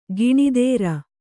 ♪ giṇidēra